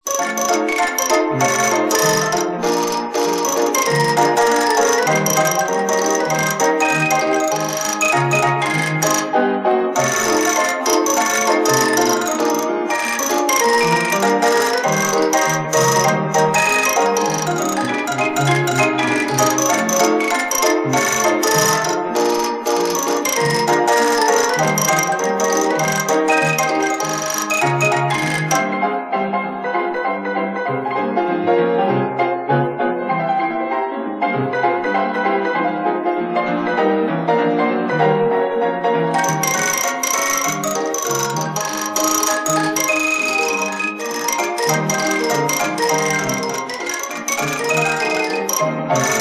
STYLE E &G NICKELODEON MUSIC